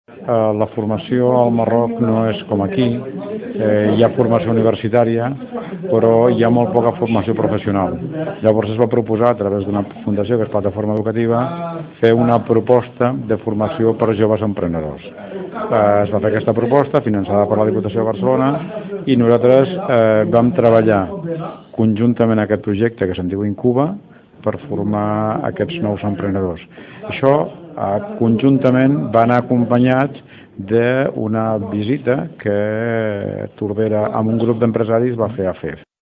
Ho explica l’alcalde de Tordera, Joan Carles Garcia.